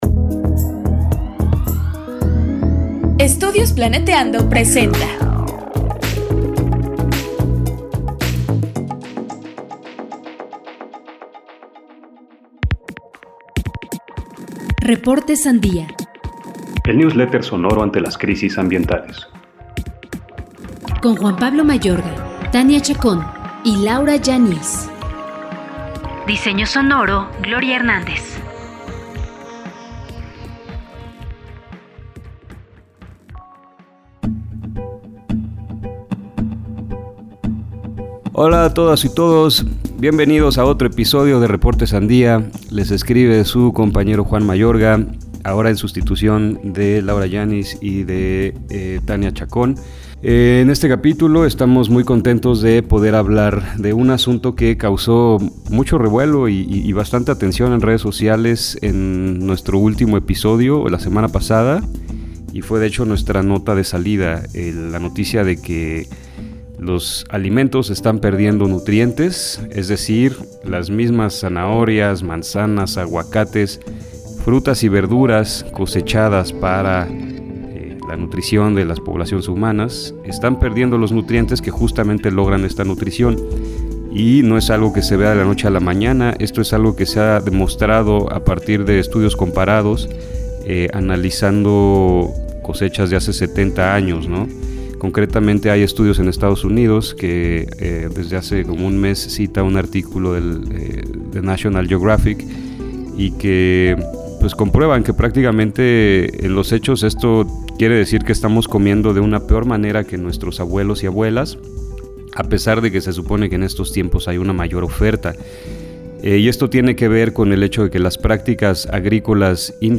Recuperar la relación con los alimentos. Entrevista